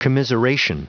Prononciation du mot commiseration en anglais (fichier audio)
Prononciation du mot : commiseration